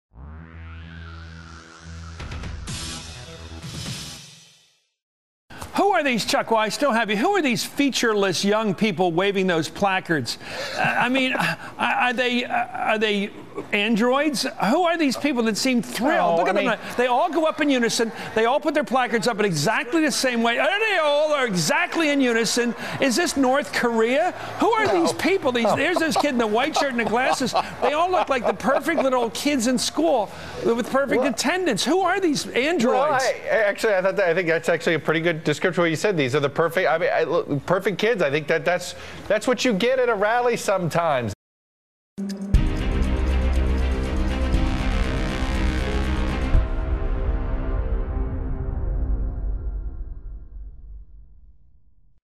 Talking to fellow MSNBC journalist Chuck Todd, Matthews spewed, "...Who are these featureless young people waving those placards? I mean, are they, are they androids?... They are all exactly in unison. Is this North Korea? Who are these people?"
A partial transcript of the March 7 segment, which aired at 5:04pm EST, follows: